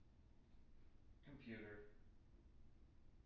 wake-word
tng-computer-240.wav